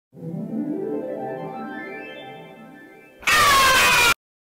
Screaming Blue Thing Meme